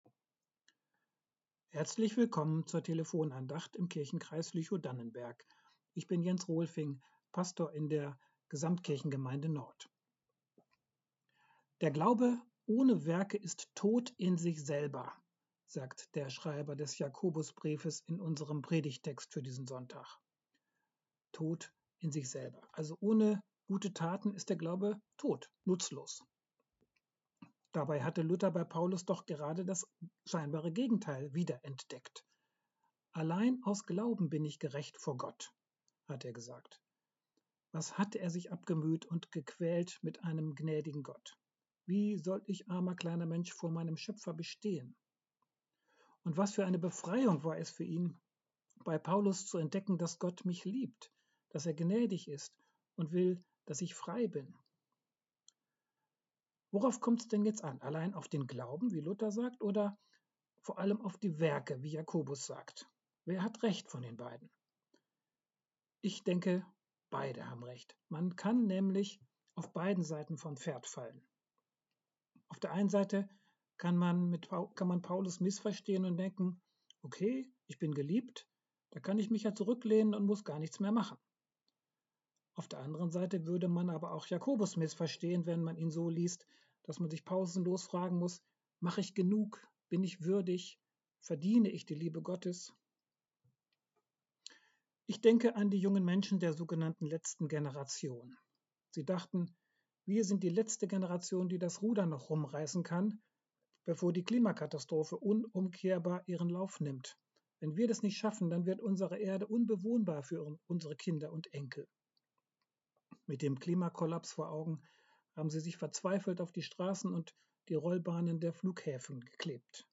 Glaube und Werke ~ Telefon-Andachten des ev.-luth. Kirchenkreises Lüchow-Dannenberg Podcast